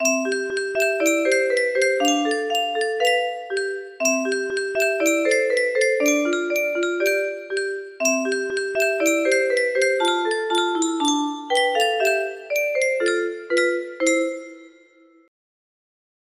사랑의 인사 music box melody